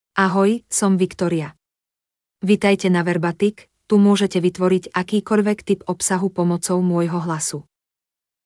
ViktoriaFemale Slovak AI voice
Viktoria is a female AI voice for Slovak (Slovakia).
Voice sample
Listen to Viktoria's female Slovak voice.
Viktoria delivers clear pronunciation with authentic Slovakia Slovak intonation, making your content sound professionally produced.